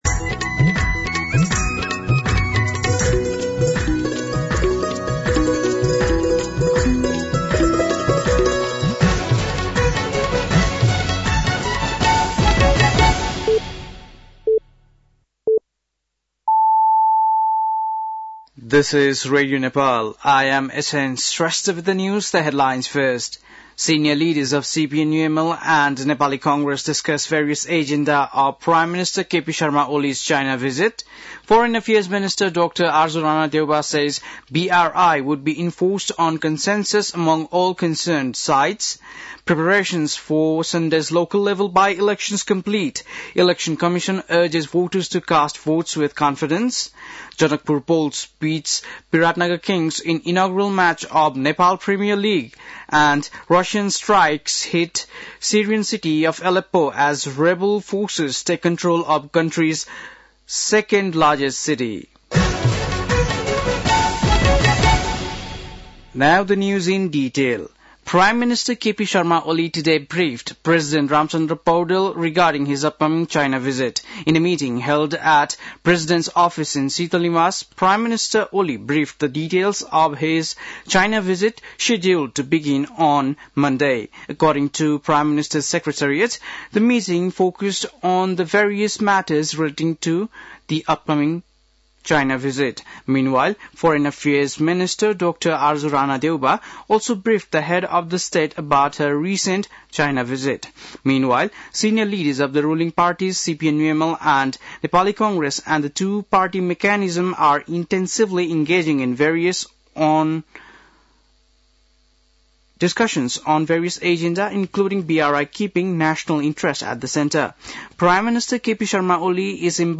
बेलुकी ८ बजेको अङ्ग्रेजी समाचार : १६ मंसिर , २०८१
8-PM-English-News-8-15.mp3